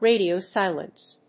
radio+silence.mp3